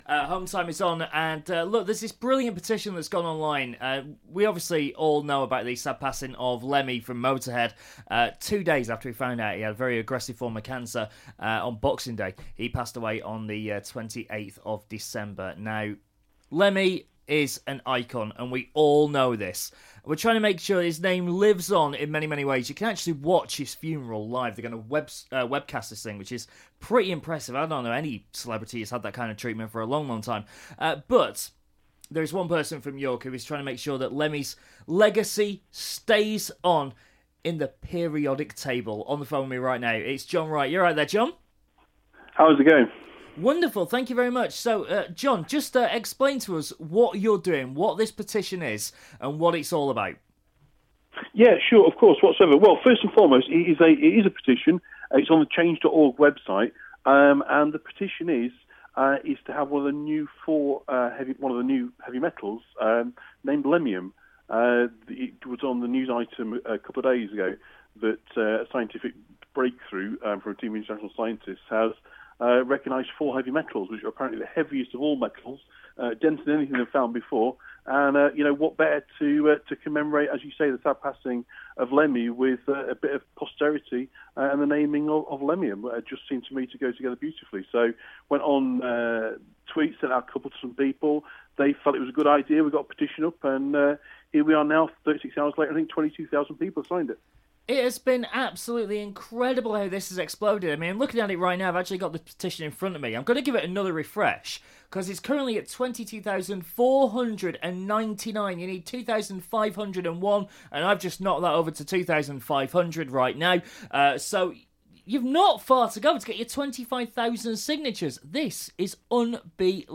York resident talks about setting up petition for a tribute to Lemmy